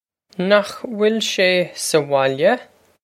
Pronunciation for how to say
nokh wil shay sah wohl-ya?
This is an approximate phonetic pronunciation of the phrase.